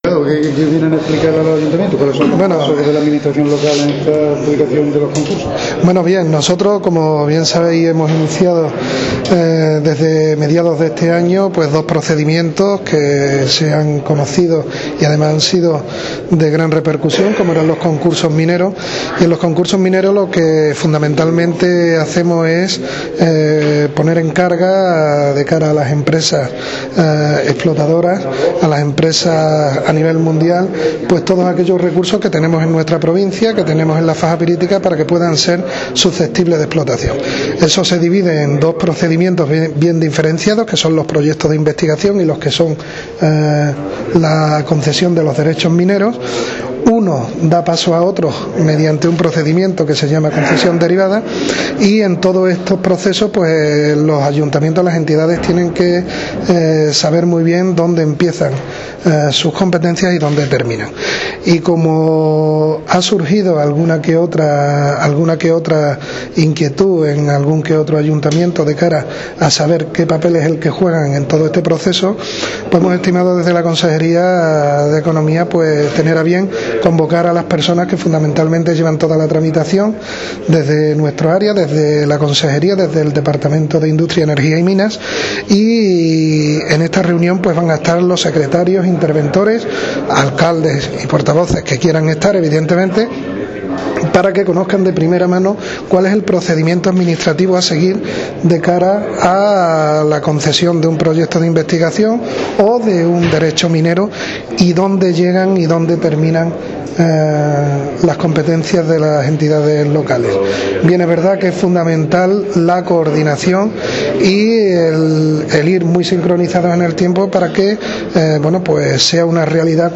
El delegado de Economía, durante la inauguración de la jornada informativa.
Delegado_Economia_jornada_informativa_concurso_minero.mp3